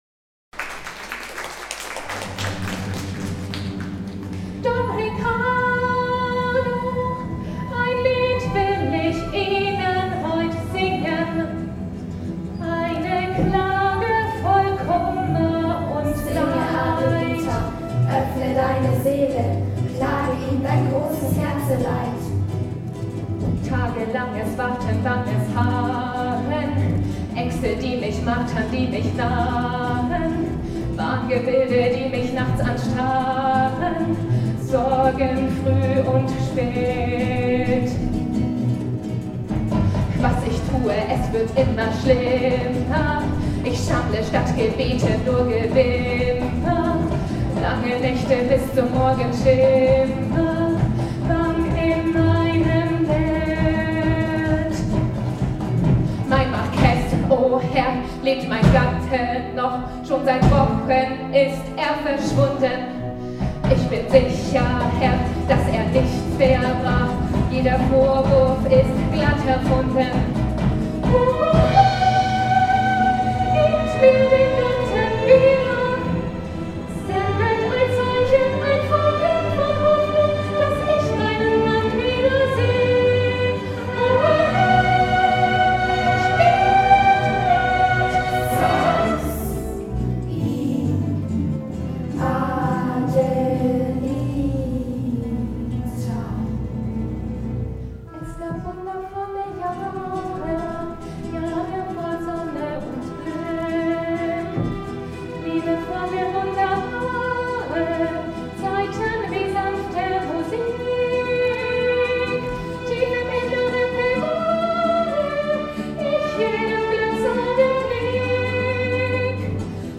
Bevor der Vikar aber seinen Segen erteilen kann, unterbricht Adelita mit ihrem Lied, in dem sie, unterstützt von den Wäscherinnen, ihr Leid klagt: